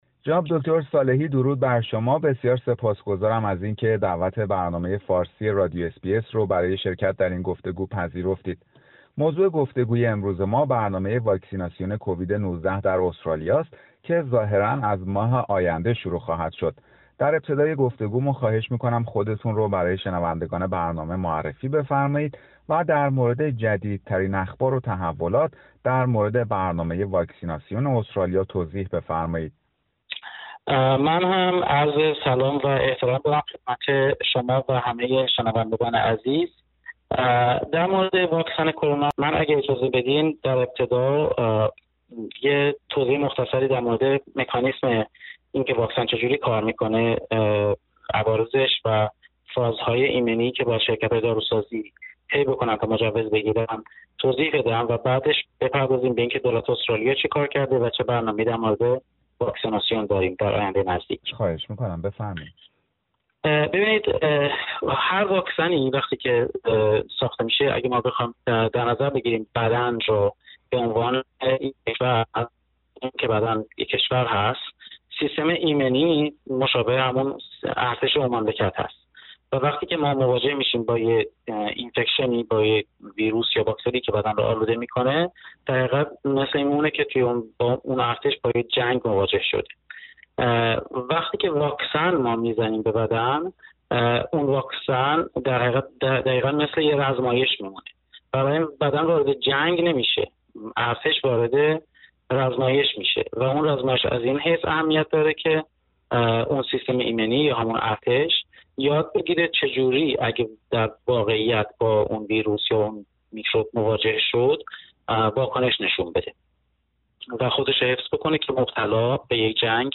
گفتگو با یک پزشک در مورد برنامه واکسیناسیون کووید-۱۹ در استرالیا